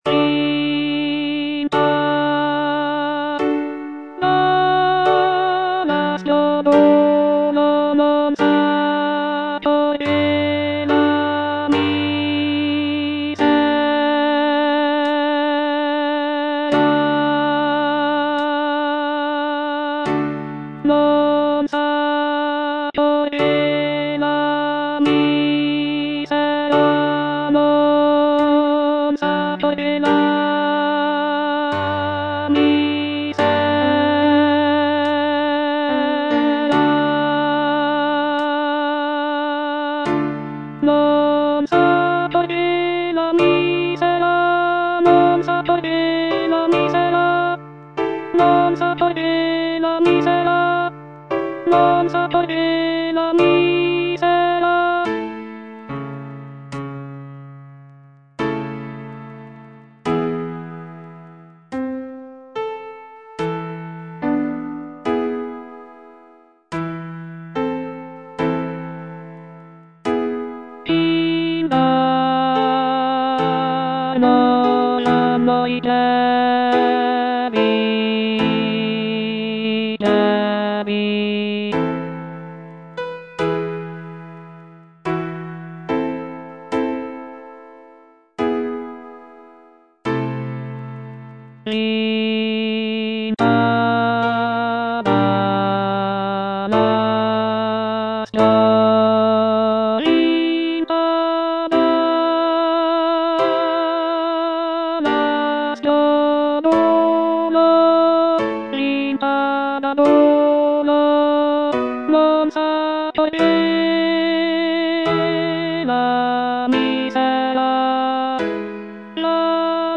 C. MONTEVERDI - LAMENTO D'ARIANNA (VERSION 2) Coro III: Vinta da l'aspro duolo - Alto (Voice with metronome) Ads stop: auto-stop